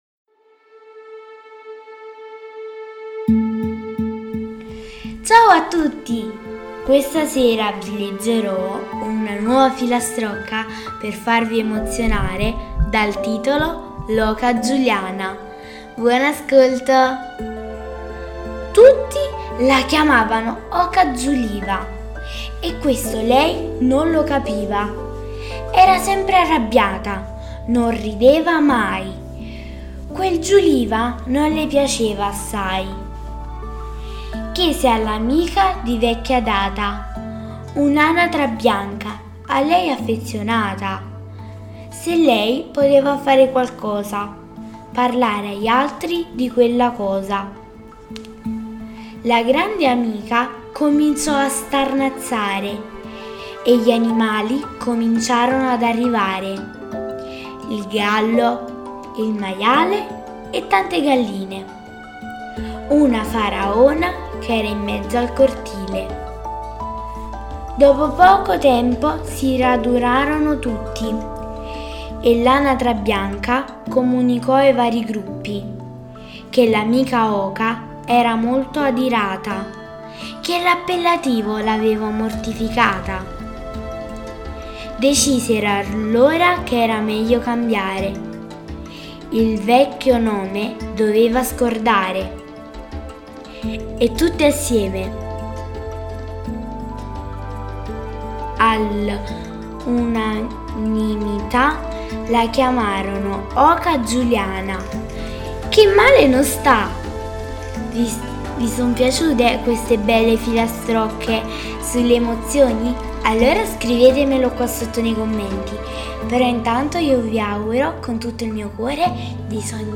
Leggi e ascolta la storia de "l'oca Giuliana"